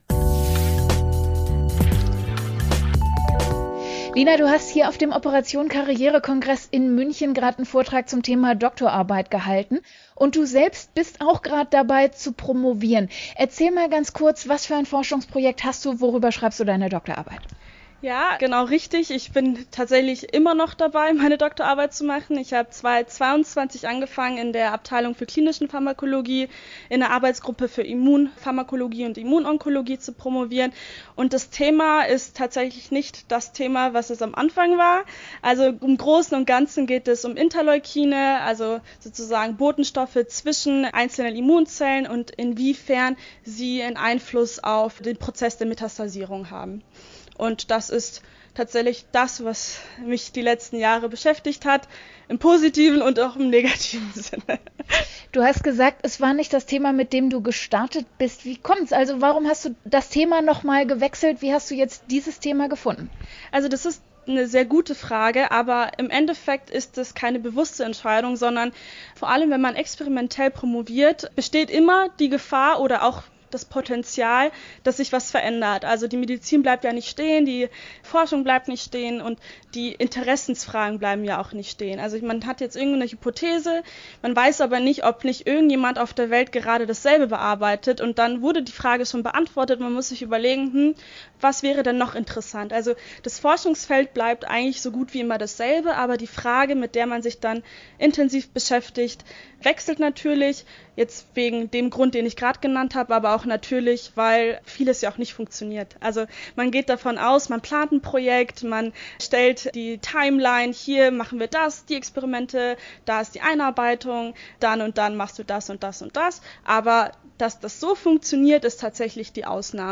Was bringt es eigentlich, eine Doktorarbeit zu schreiben – mal abgesehen vom Titel? Wie geht man mit Rückschlägen um und was lernt man dabei – nicht nur wissenschaftlich, sondern auch für die eigene Persönlichkeitsentwicklung? Darüber spricht im Interview...